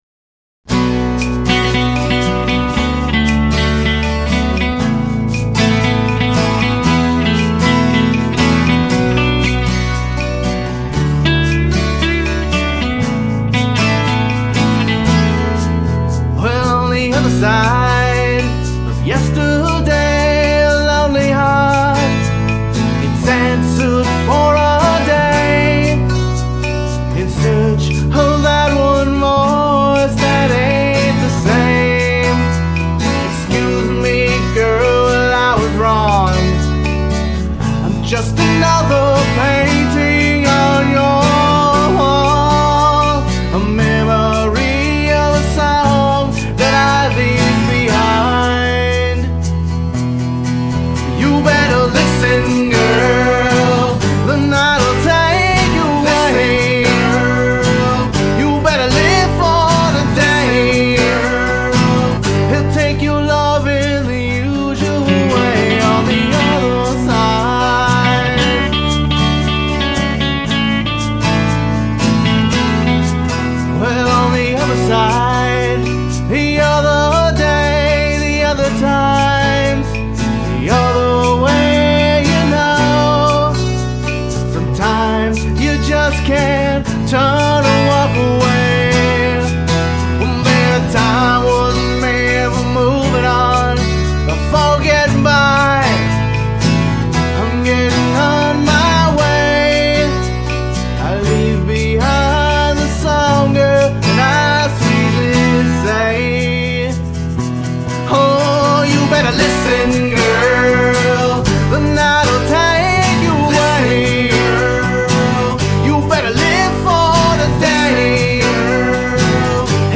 Vocals, Guitars, Bass, Harmonica, Trumpet